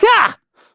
Kibera-Vox_Attack3.wav